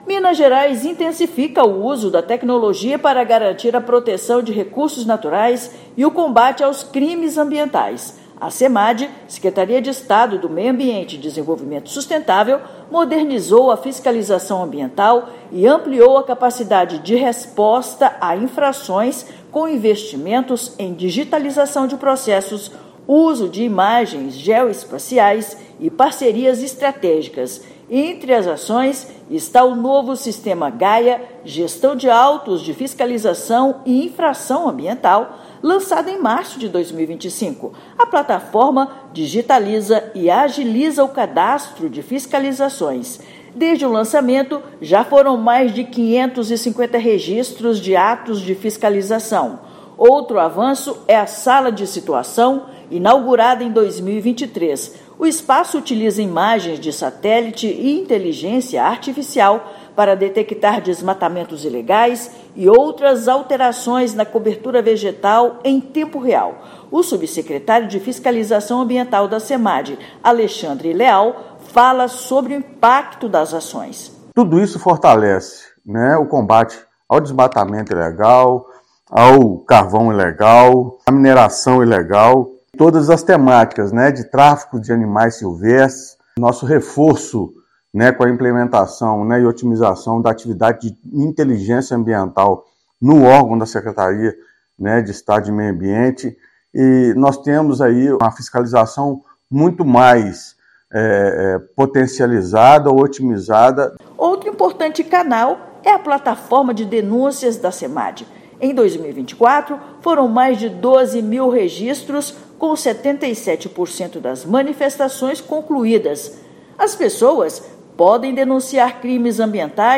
Digitalização, monitoramento por satélite, inteligência ambiental e parcerias institucionais fortalecem a fiscalização ambiental no estado. Ouça matéria de rádio.